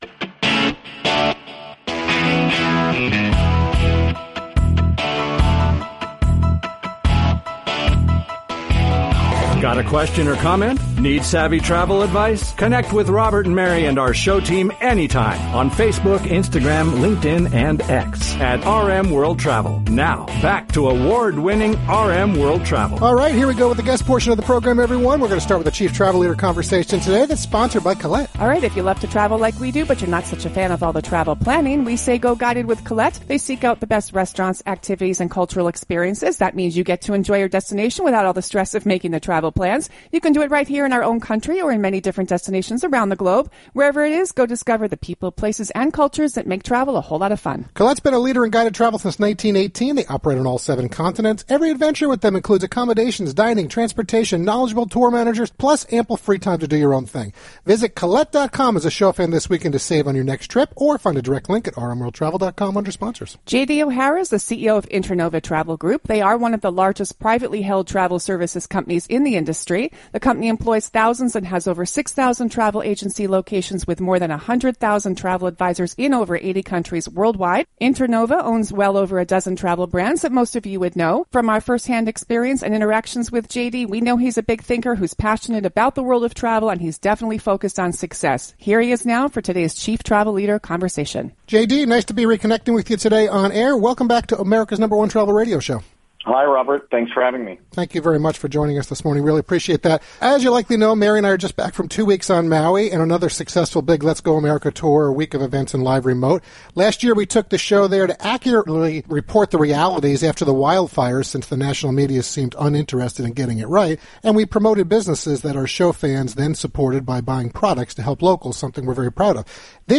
live broadcast of America’s #1 Travel Radio Show